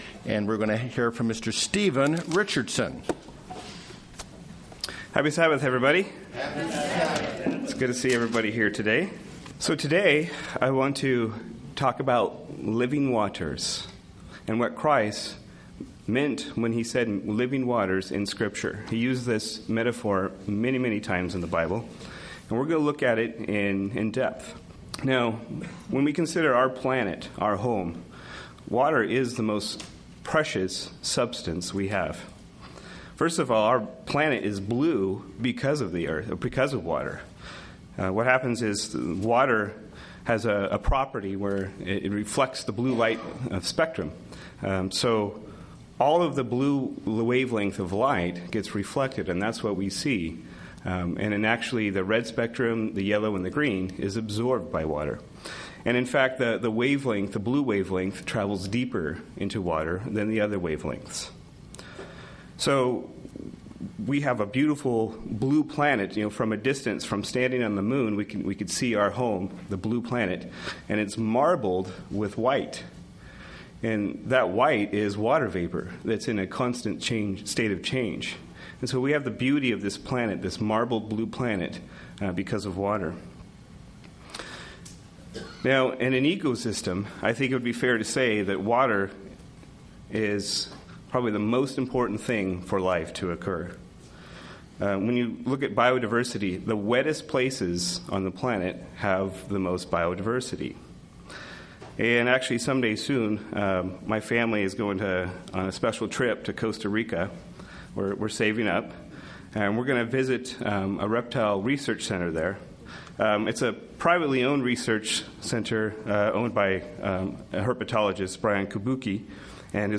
Given in Redlands, CA
We thirst for righteousness - John 7:37-38 UCG Sermon Studying the bible?